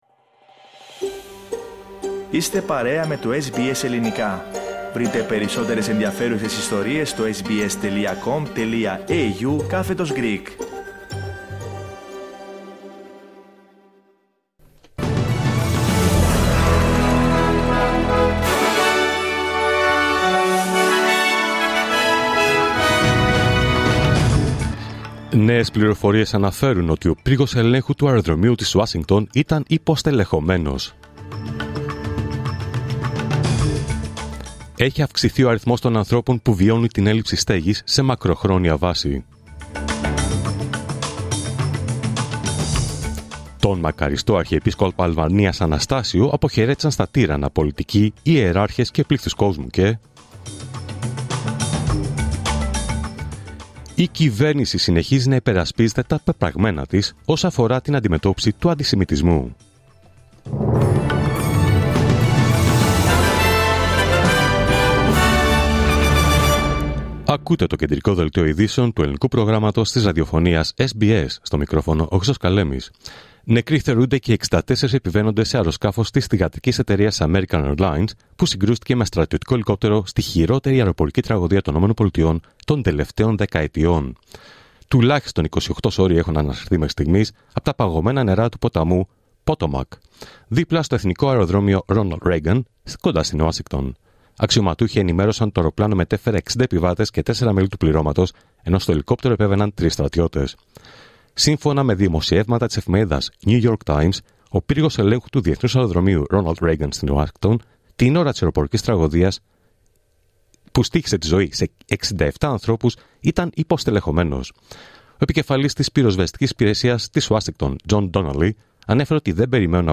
Δελτίο Ειδήσεων Παρασκευή 31 Ιανουαρίου 2025